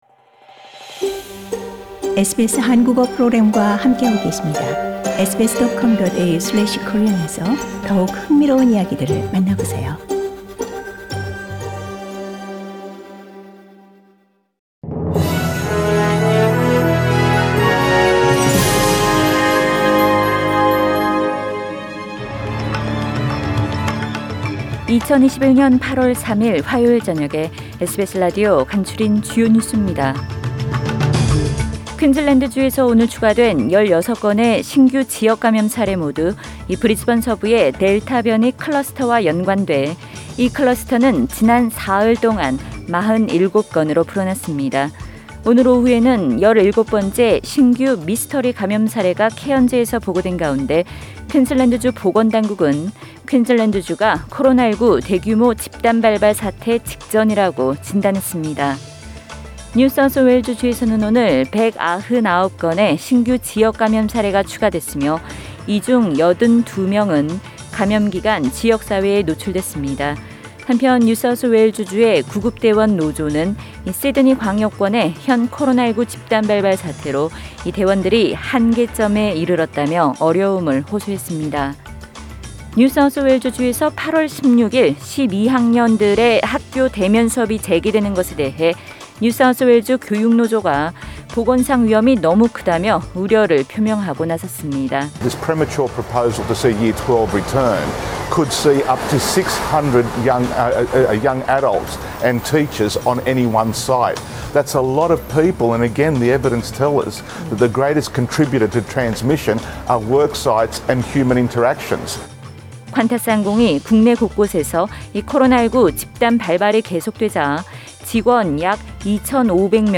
2021년 8월 3일 화요일 저녁의 SBS 뉴스 아우트라인입니다.